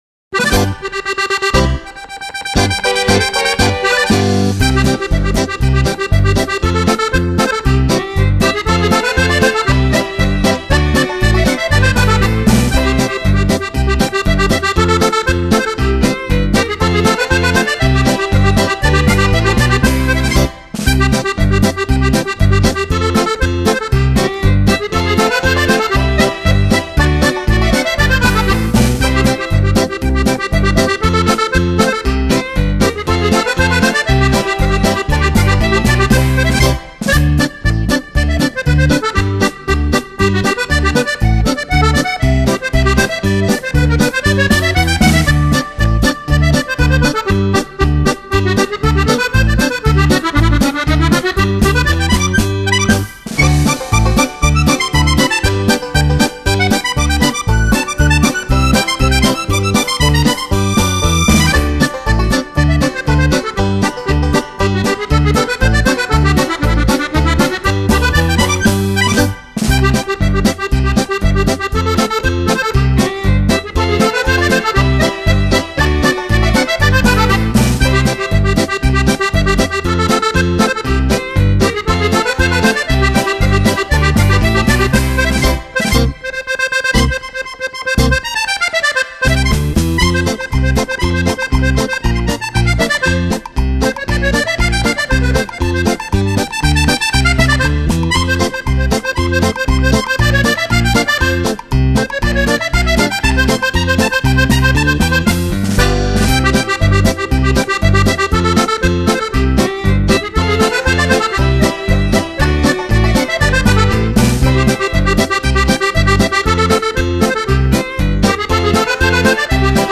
Genere: Polka